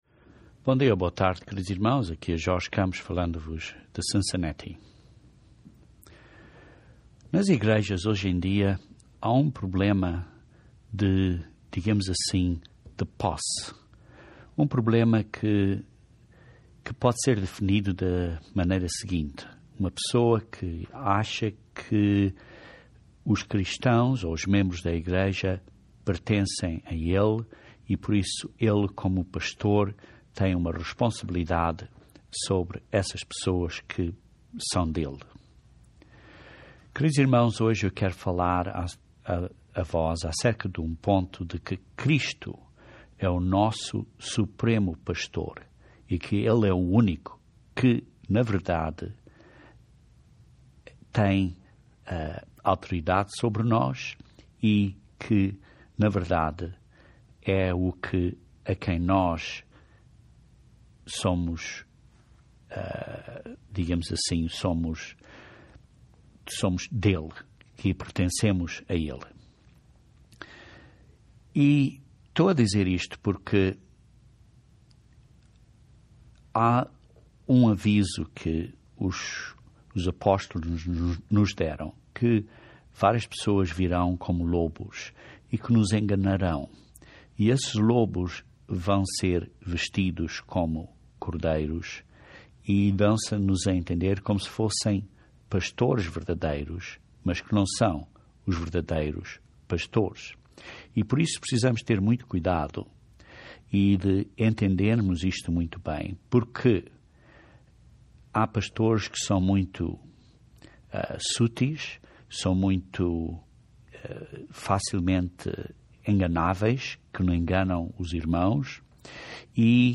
Este sermão analisa este problema de "posse".